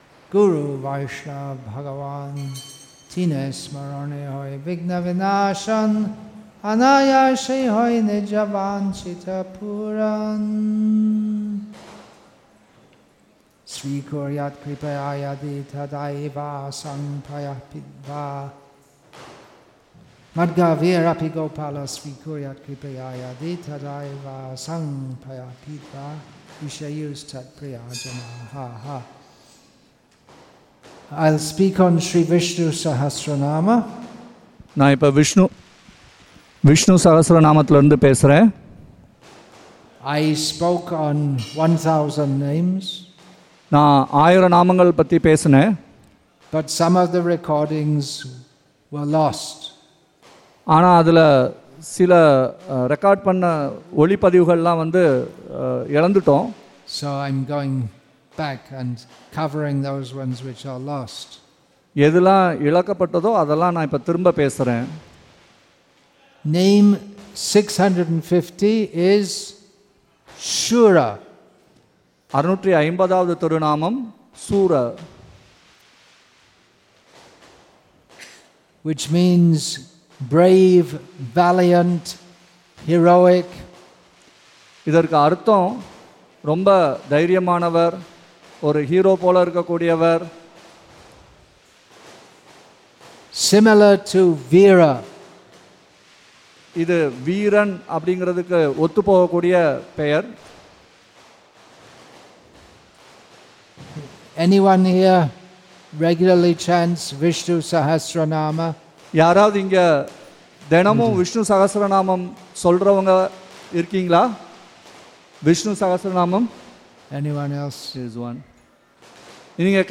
English with தமிழ் (Tamil) Translation; Chennai, Tamil Nadu , India